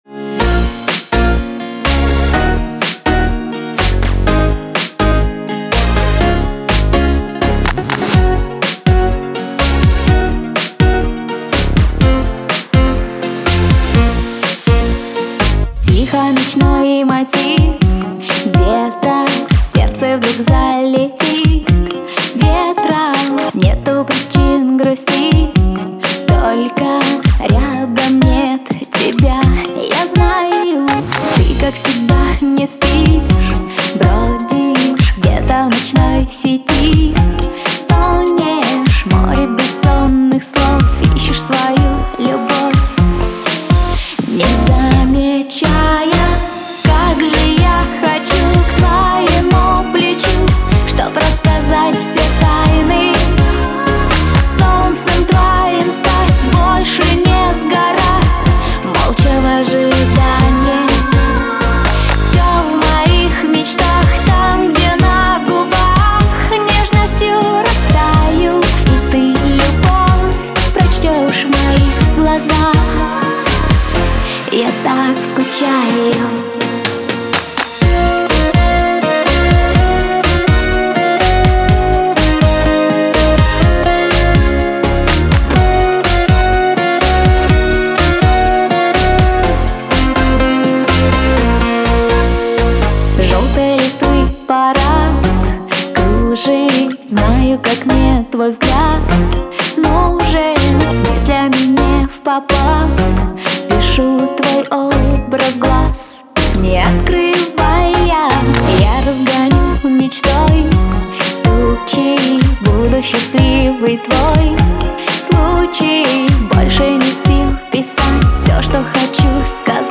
Жанр: Только качественная POP музыка